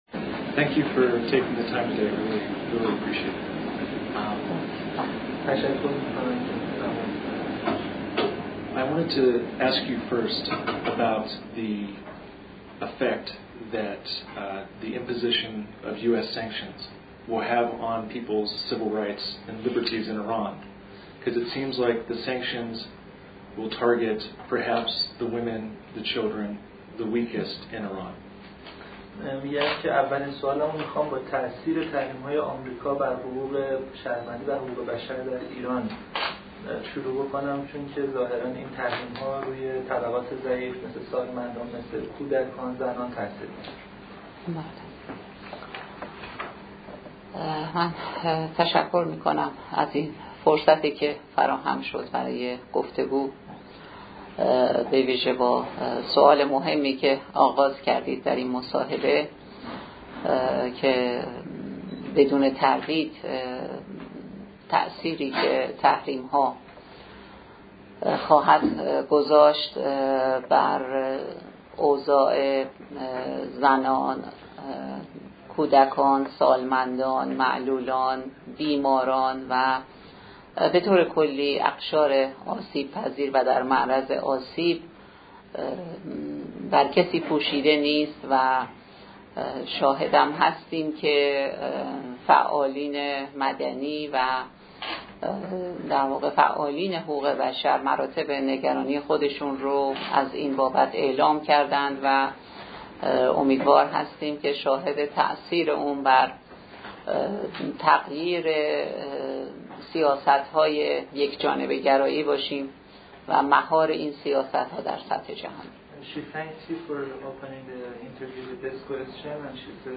فایل صوتی‌ مصاحبه مولاوردی با AP - تسنیم